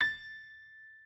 piano6_32.ogg